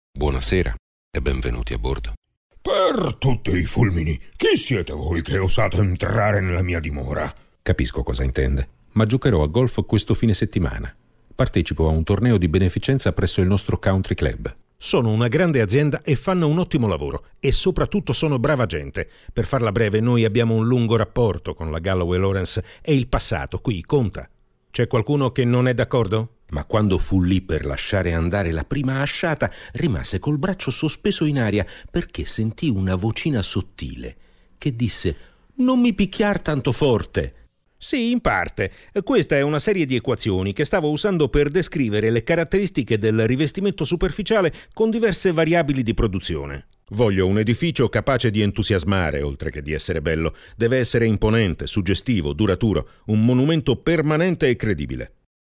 I'm a native Italian voice talent, with a solid background in radio-tv journalism and documentary making, and a real italian regional accent-free voice: mid/low range, 30s to 50s, informative, elegant, believable, professional, yet warm, friendly, seductive when needed.
I provide studio-quality recordings with .wav or .mp3 fast, reliable, safe online delivery.
Sprecher italienisch. Middle age deep voice
Sprechprobe: Sonstiges (Muttersprache):